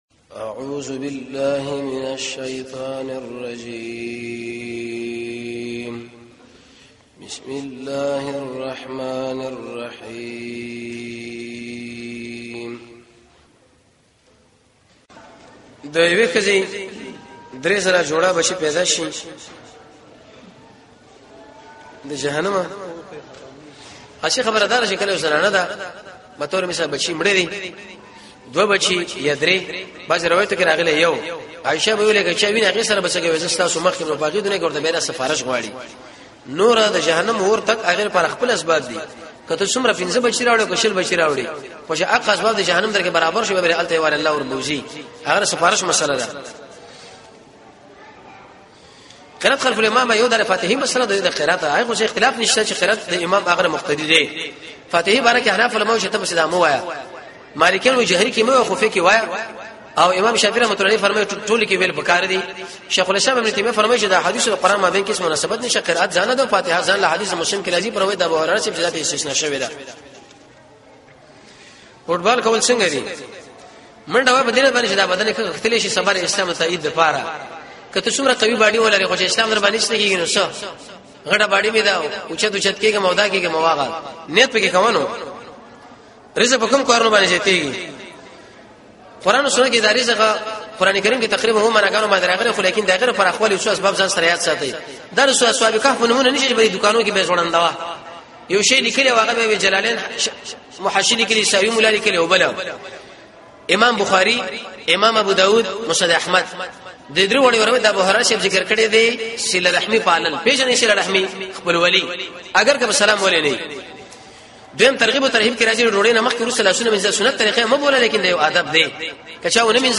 ۷ - پوښتنه او ځواب